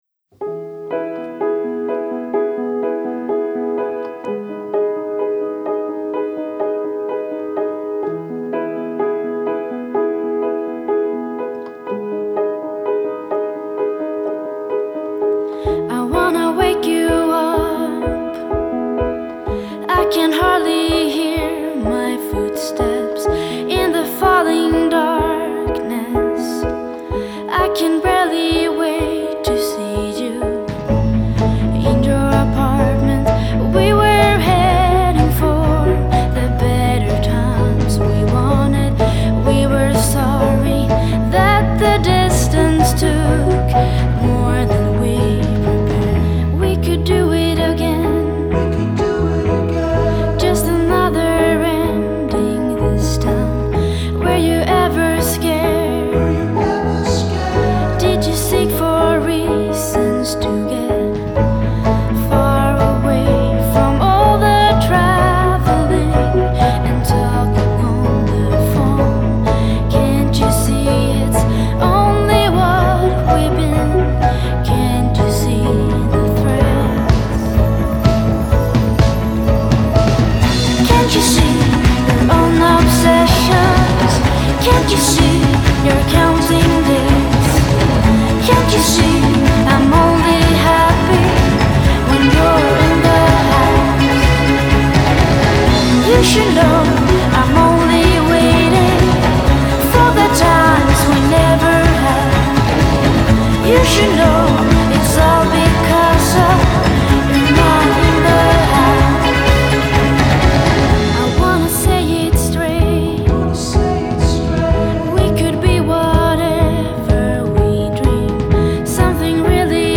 Swedish pop